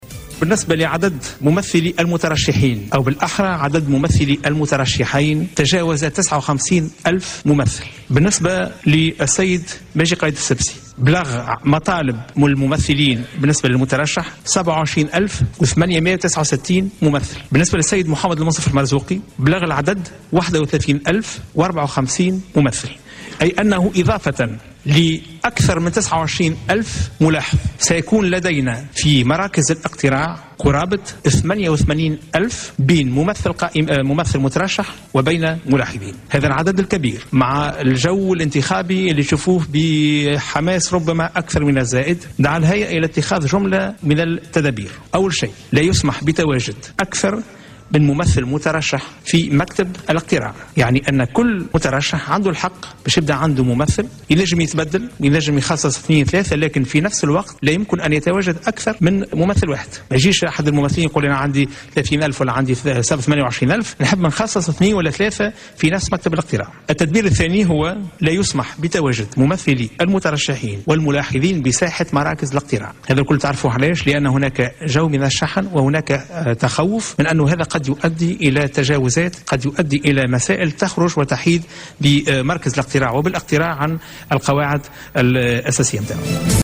أكد رئيس الهيئة العليا المستقلة للانتخابات شفيق صرصار خلال ندوة صحفية انعقدت اليوم الخميس أن العدد الجملي لممثلي المترشحين للانتخابات الرئاسية لمراقبة العملية الانتخابية في مكاتب الاقتراع بلغ حوالي 59 ألف ممثل.